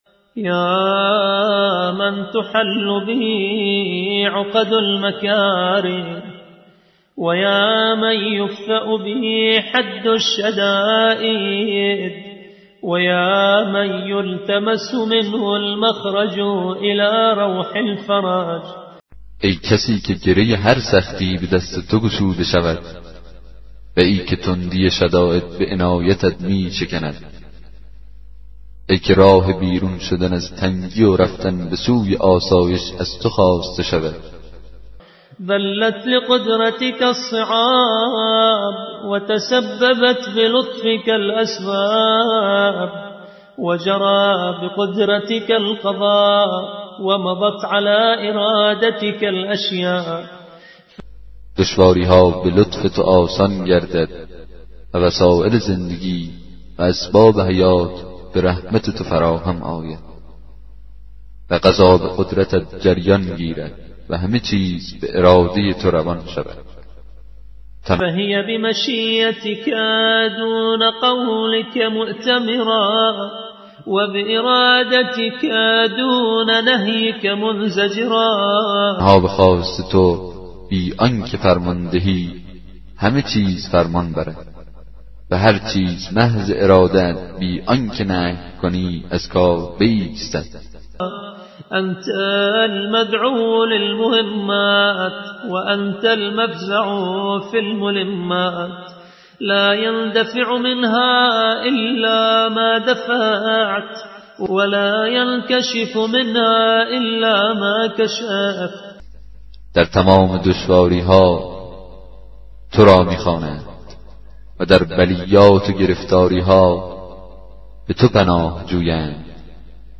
کتاب صوتی دعای 7 صحیفه سجادیه